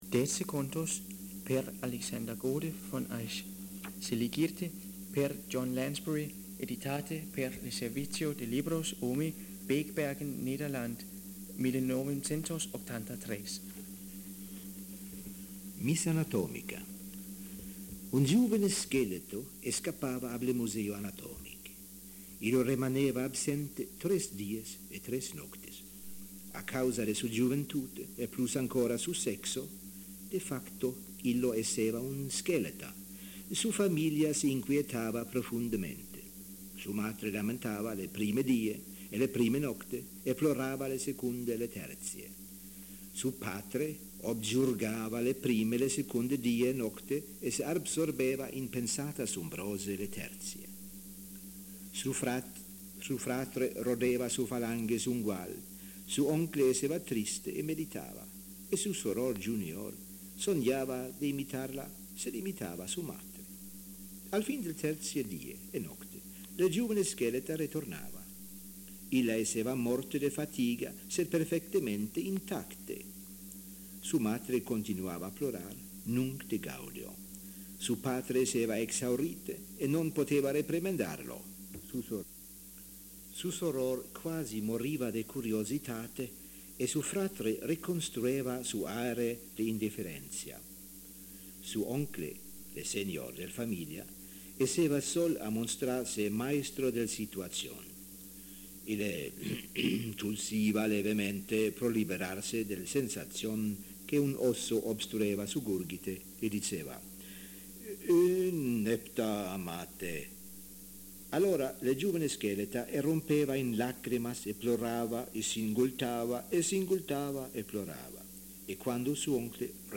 Durante le 7me Conferentia International de Interlingua in Danmark in 1985, un serie de personas registrava le dece contos originalmente seligite per John Lansbury e primo publicate in 1958.